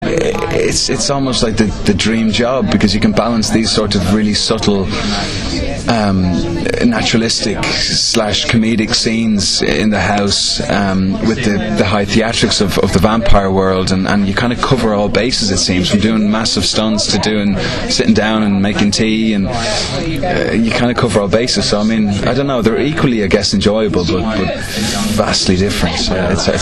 Now here are a few extracts, with audio, from the interviews with Aidan and Lenora, who is also currently starring as fashion designer Ali in BBC1 drama series Material Girl: